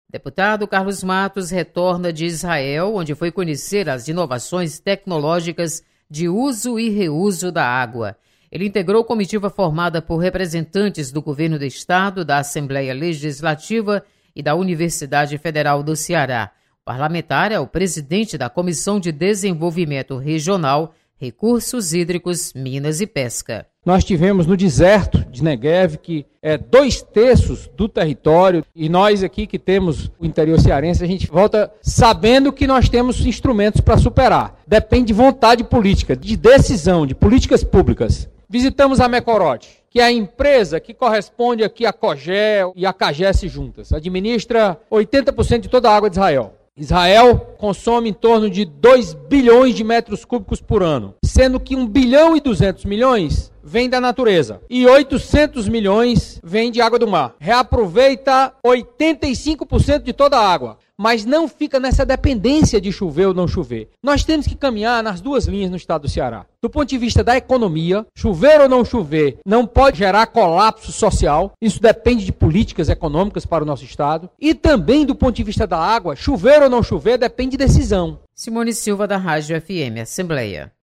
Exemplo de Israel pode ser solução para crise hídrica no Ceará, defende o deputado Carlos Matos. Repórter